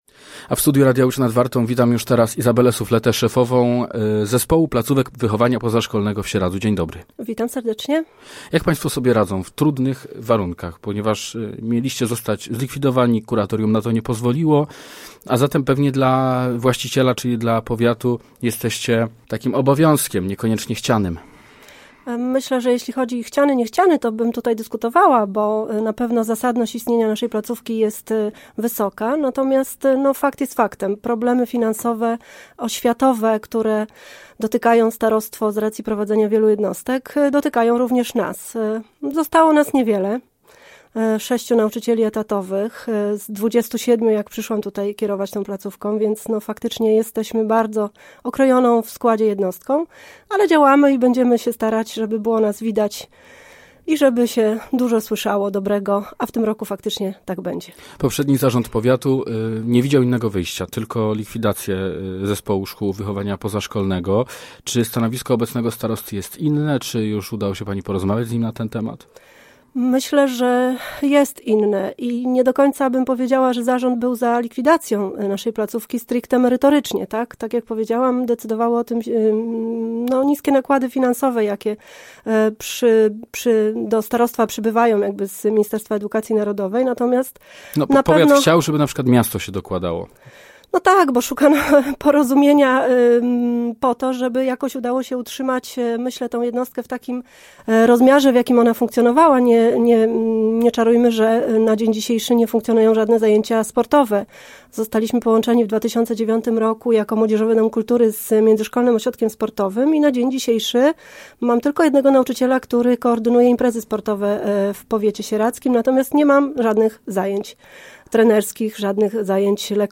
Posłuchaj całej rozmowy: Nazwa Plik Autor – brak tytułu – audio (m4a) audio (oga) Warto przeczytać Fly Fest 2025.